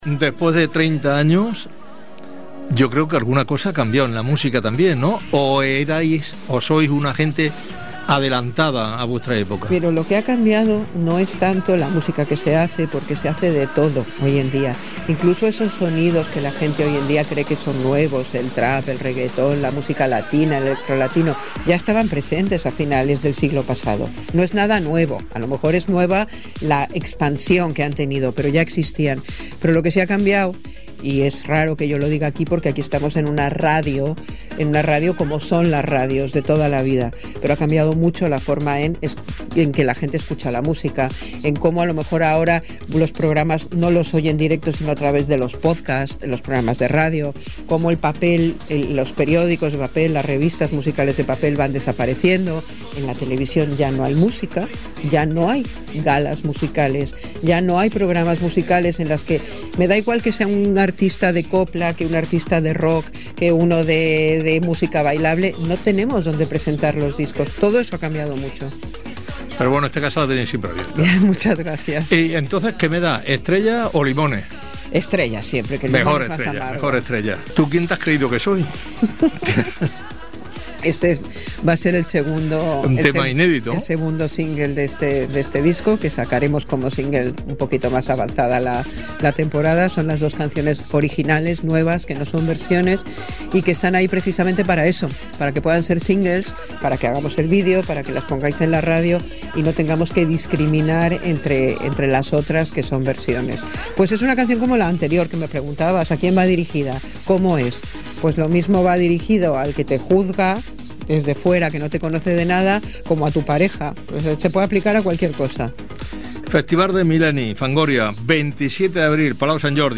Entrevista a la cantant Alaska (Olvido Gara) del grup Fangoria sobre l'últim tema enregistrat i la gira que estan fent
Entreteniment